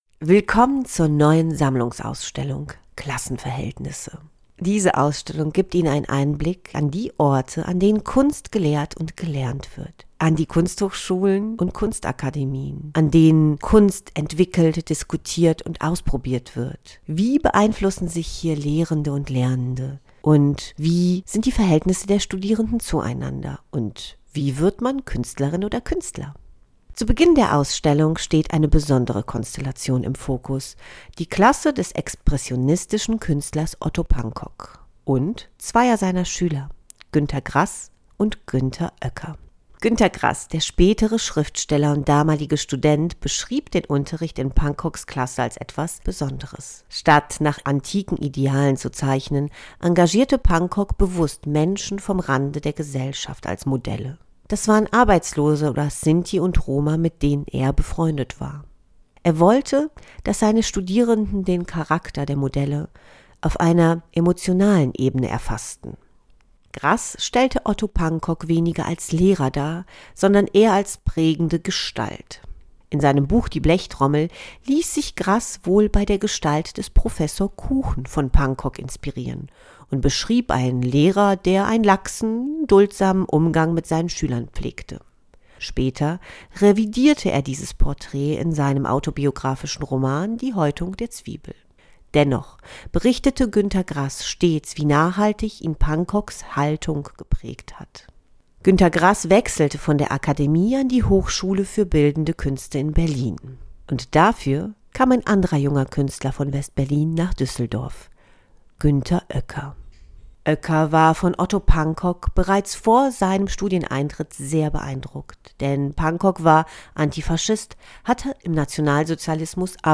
Sie können die einleitenden Texte zu den Ausstellungskapitel und zentralen Themen auch als Podcast hören.